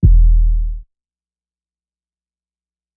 The Wave 808 - D#.wav